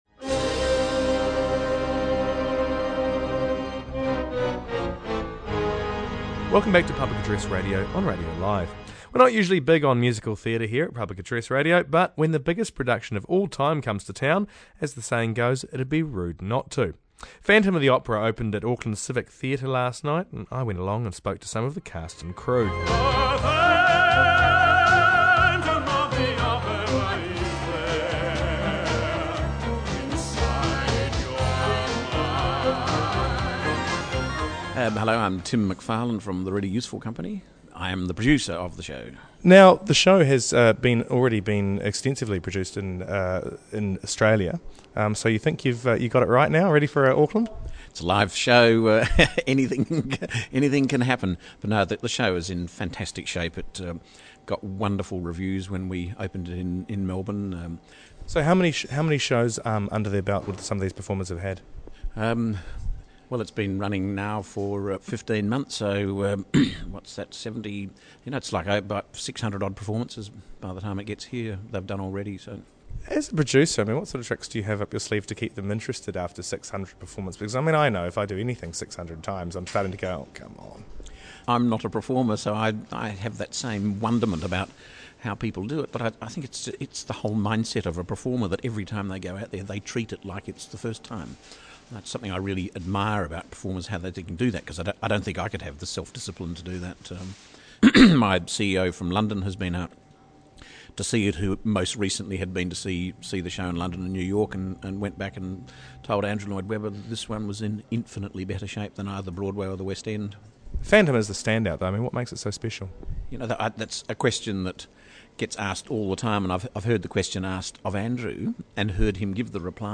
heads to the media preview of the Phantom of the Opera, which started at Auckland's Civic theatre this week, and talks to the cast and crew.